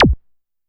071 MG Blip.wav